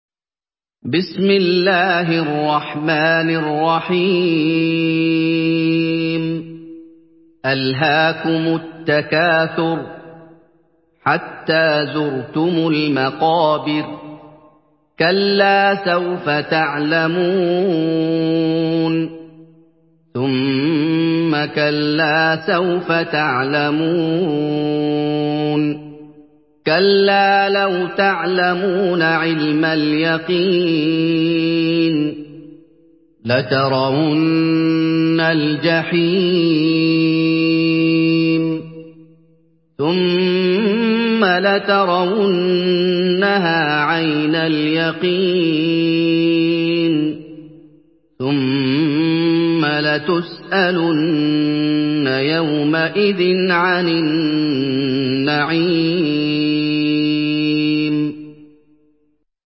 Surah আত-তাকাসুর MP3 by Muhammad Ayoub in Hafs An Asim narration.
Murattal Hafs An Asim